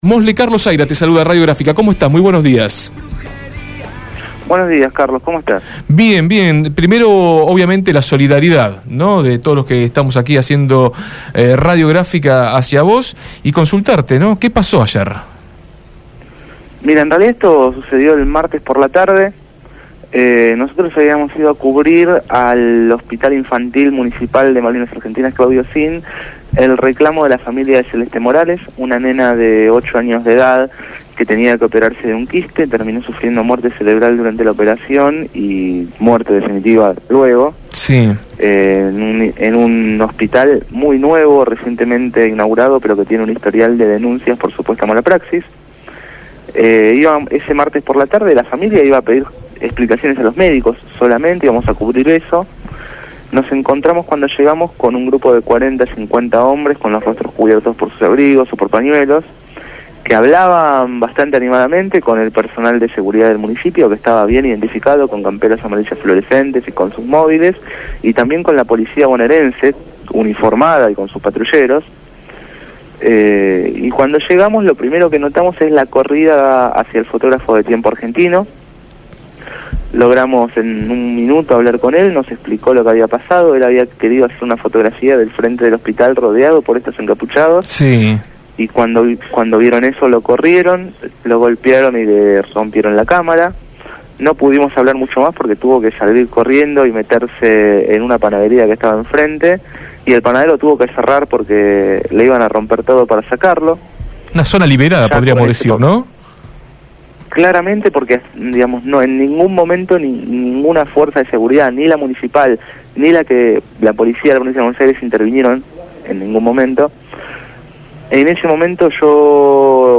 Testimonio de uno de los periodistas agredidos en el partido de Malvinas Argentinas